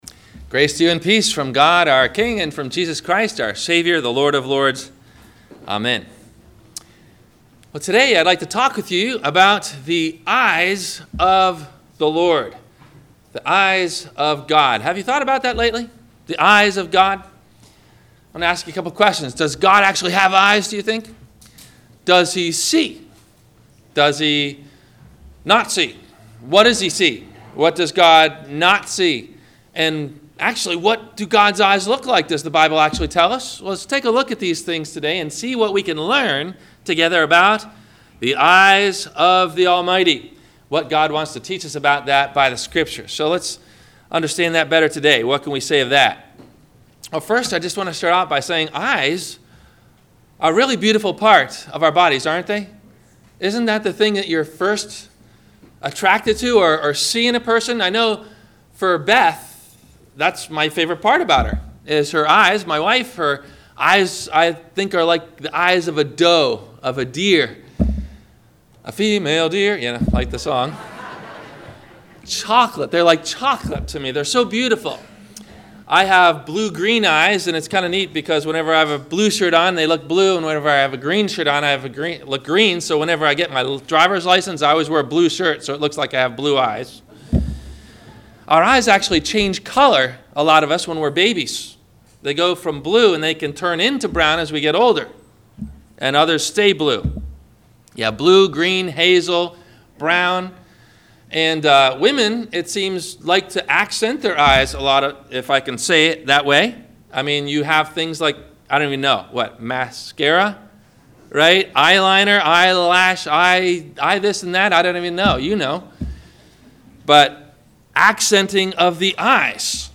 The Eyes of The Lord - Sermon - July 16 2017 - Christ Lutheran Cape Canaveral